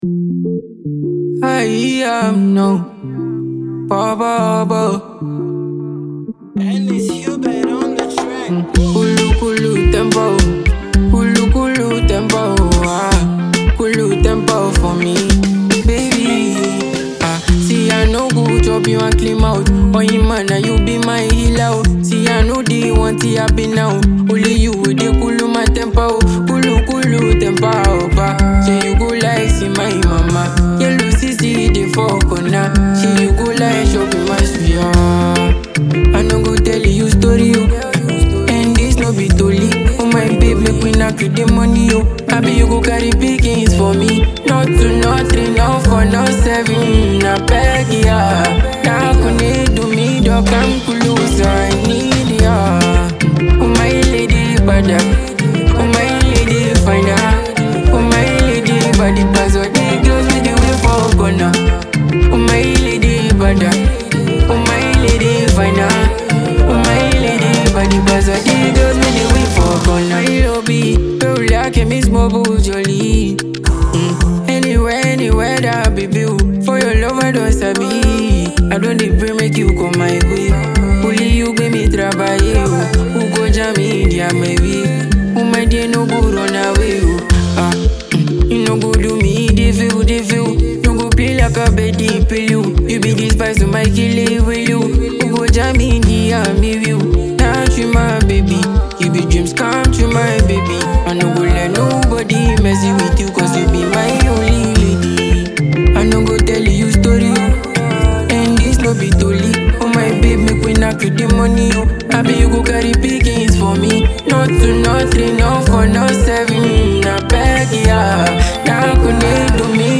heartfelt Afrobeat love song with a smooth Highlife touch